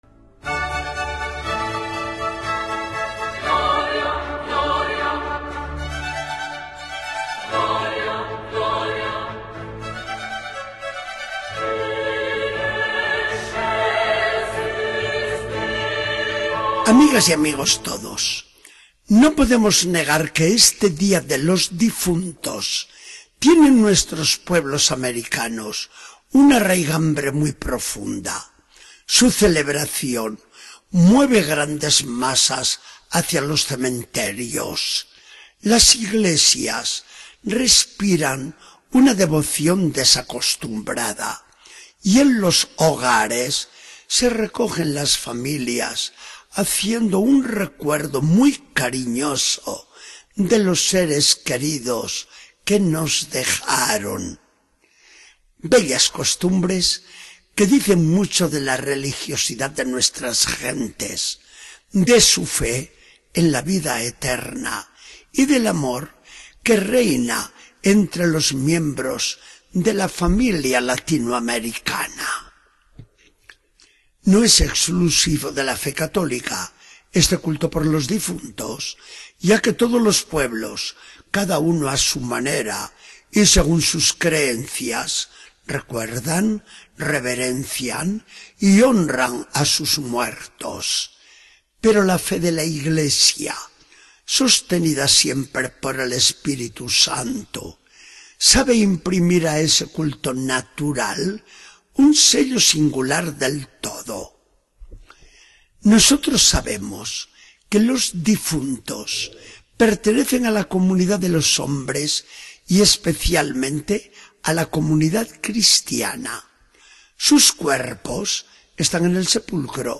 Charla del día 2 de noviembre de 2014. Del Evangelio según San Lucas 24, 1-8.